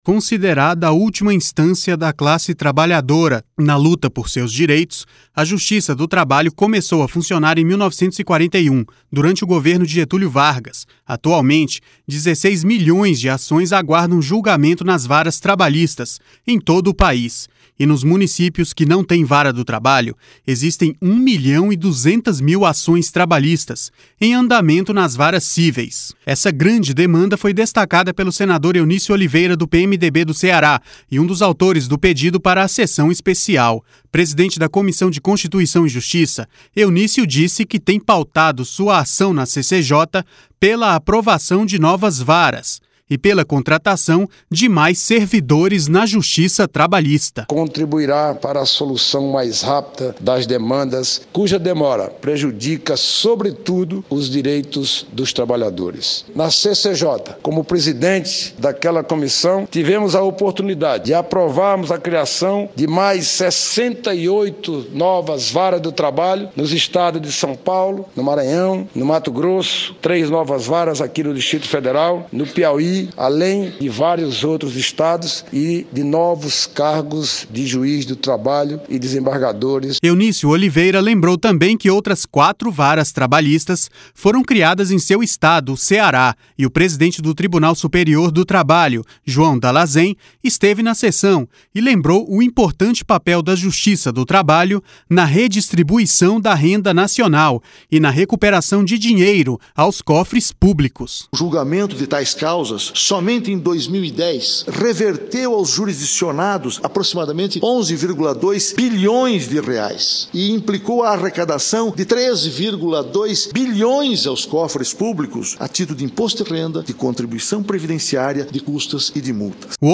Essa grande demanda foi destacada pelo senador Eunício Oliveira, do PMDB do Ceará e um dos autores do pedido para a sessão especial.